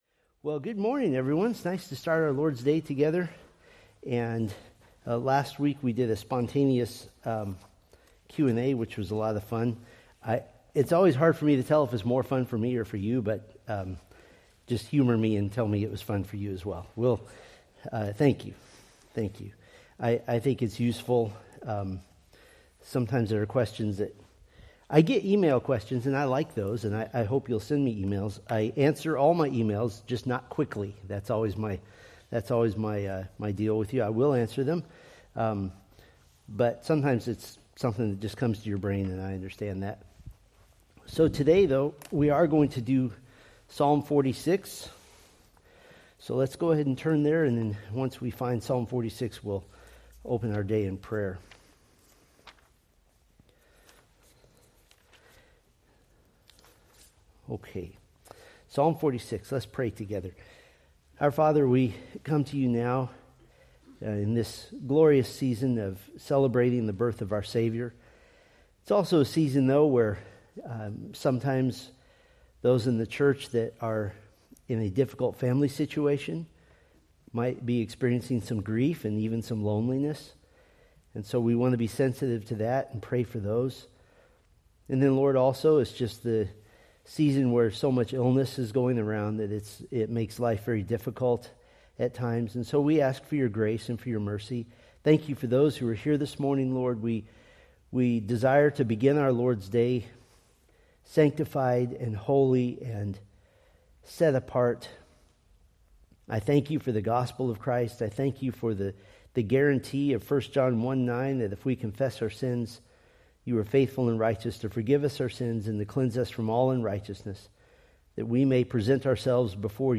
Date: Dec 28, 2025 Series: Psalms Grouping: Sunday School (Adult) More: Download MP3